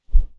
Close Combat Swing Sound 86.wav